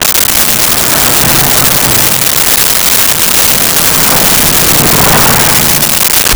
Car Multiple Fast By
Car Multiple Fast By.wav